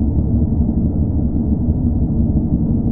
liftamb.wav